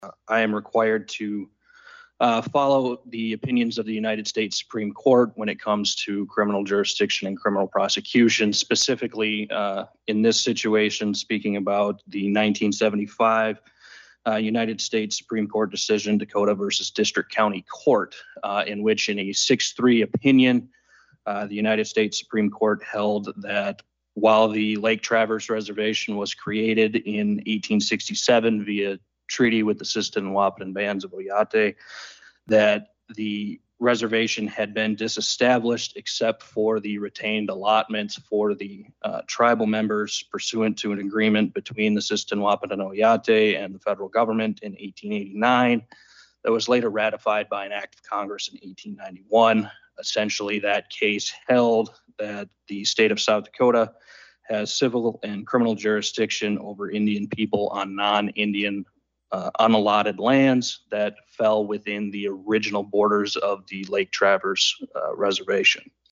AGENCY VILLAGE, S.D.(HubCityRadio)- On Thursday, the first meeting of the Interim State Tribal Relations Committee was held at the Sisseton-Wahpeton Oyate Headquarters at Agency Village.
Roberts County State’s Attorney Dylan Kirchmeier talks about jurisdiction that the county can do based on a U.S. Supreme Court decision.